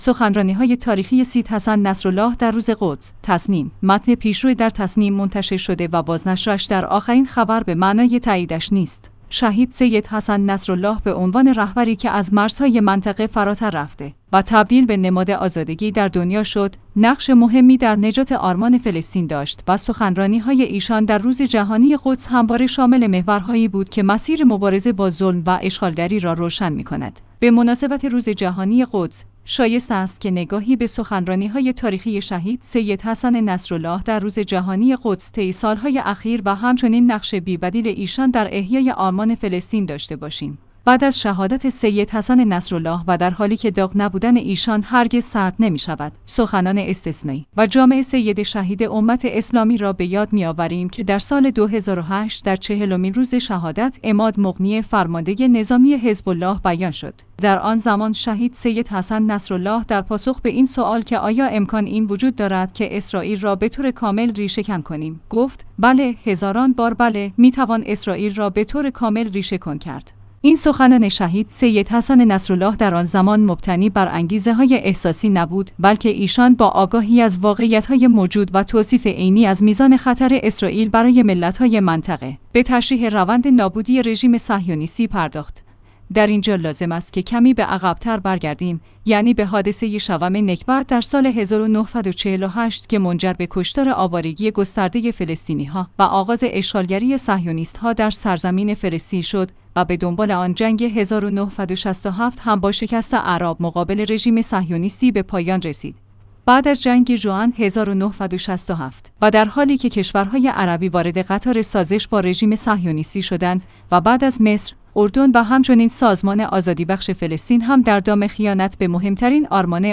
سخنرانی‌های تاریخی سیدحسن نصرالله در روز قدس